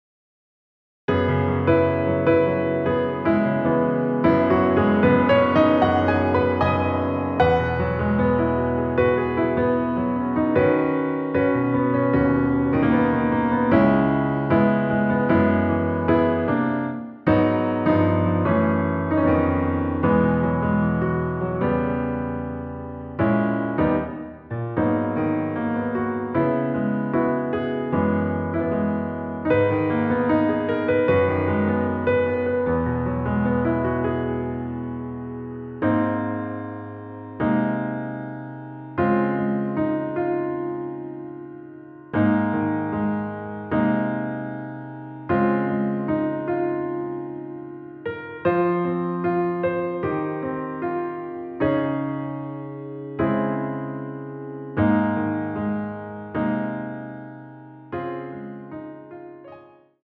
처음 시작을 후렴구로 시작을 하고 앞부분 “드릴것이 없었기에 ~ 기억도 나지 않네요” 삭제된 편곡 입니다.
Ab
앞부분30초, 뒷부분30초씩 편집해서 올려 드리고 있습니다.
중간에 음이 끈어지고 다시 나오는 이유는